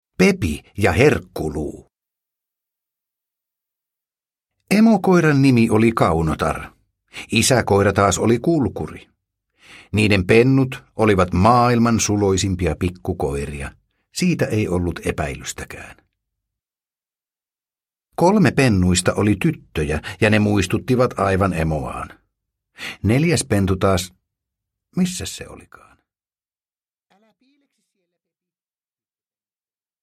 Pepi ja herkkuluu – Ljudbok – Laddas ner